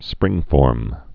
(sprĭngfôrm)